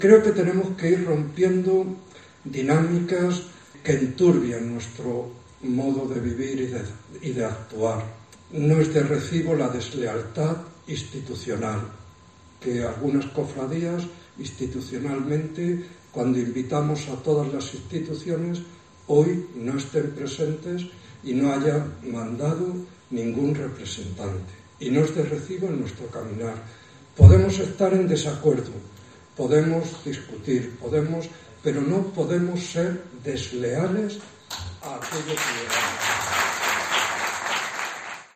El Obispo de Zamora, sobre la ausencia de varias cofradías de Semana Santa en la asamblea general